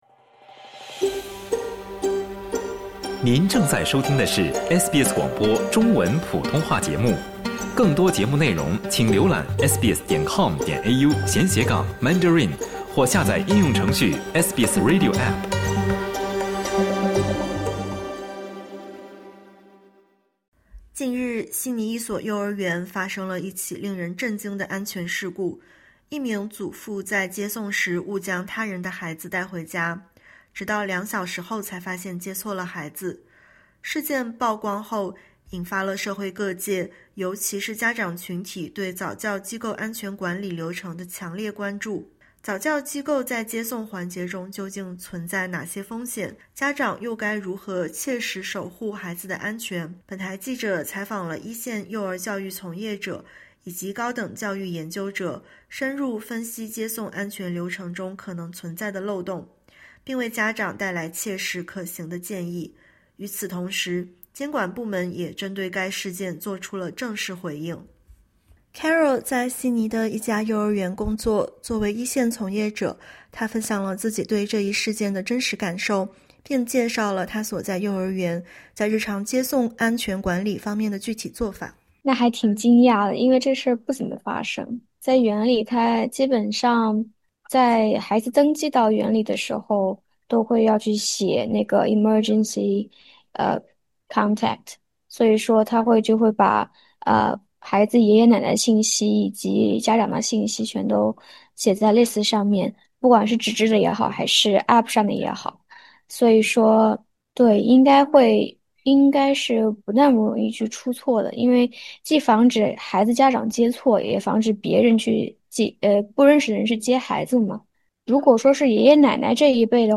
10:10 Source: AAP SBS 普通话电台 View Podcast Series Follow and Subscribe Apple Podcasts YouTube Spotify Download (9.32MB) Download the SBS Audio app Available on iOS and Android 安全漏洞出现时，谁最应该被追责？